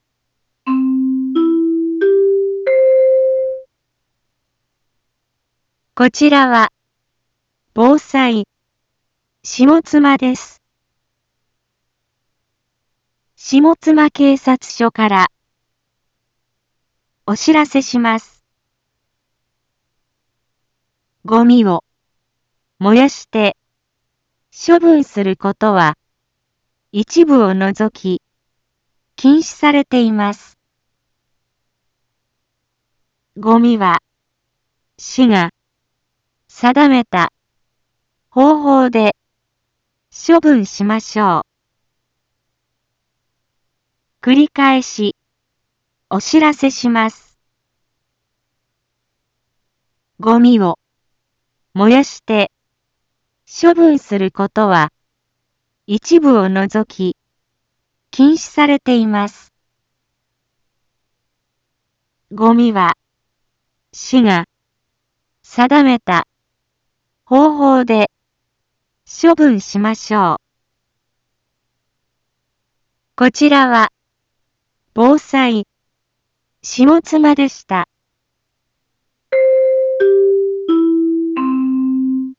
一般放送情報
Back Home 一般放送情報 音声放送 再生 一般放送情報 登録日時：2023-09-25 10:01:26 タイトル：ごみの野焼き禁止（啓発放送） インフォメーション：こちらは、防災、下妻です。